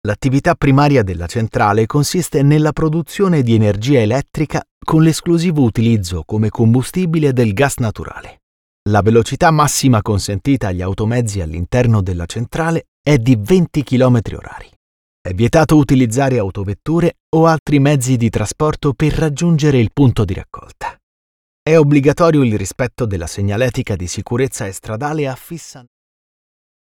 Male
Bright, Engaging, Friendly, Versatile, Authoritative, Character
Microphone: Neumann TLM 103, Universal Audio Sphere Dlx